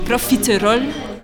prononciation
profiterol-pron.mp3